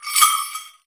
AFRICANBELL.wav